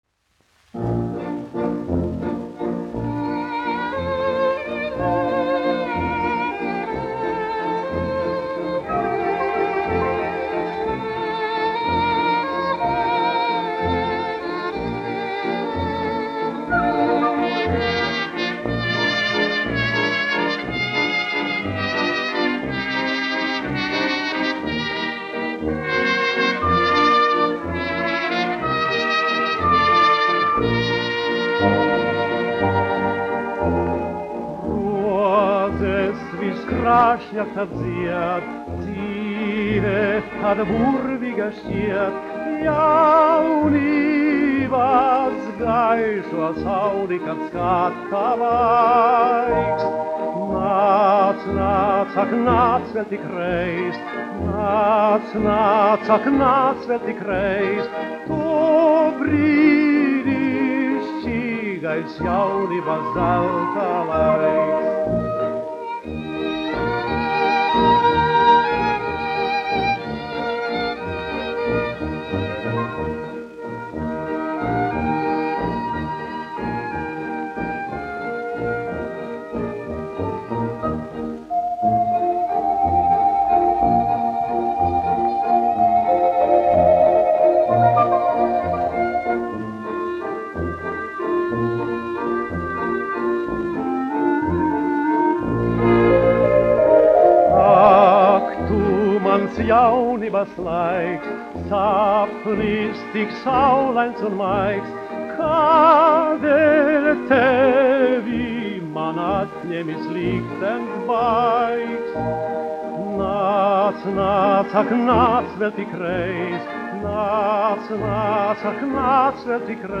dziedātājs
1 skpl. : analogs, 78 apgr/min, mono ; 25 cm
Populārā mūzika
Skaņuplate